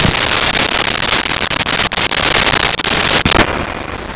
Tv Old On Off 01
TV Old On Off 01.wav